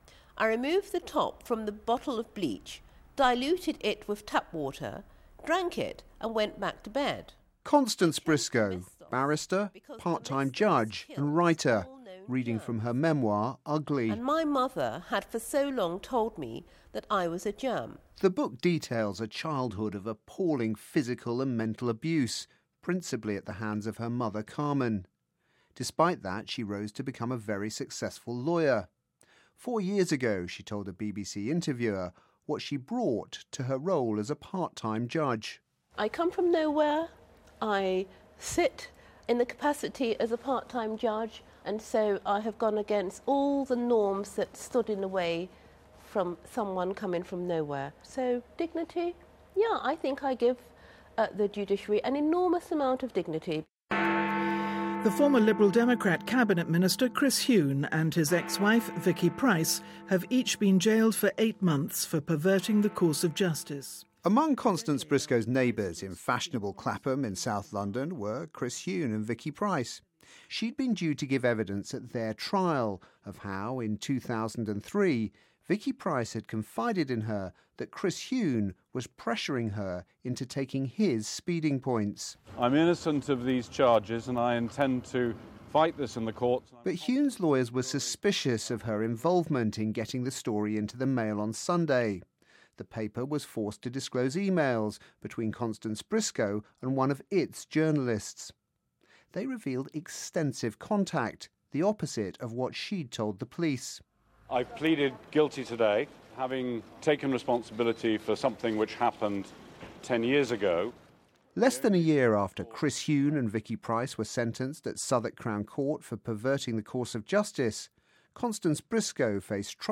report for BBC Radio 4's PM